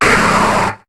Cri de Snubbull dans Pokémon HOME.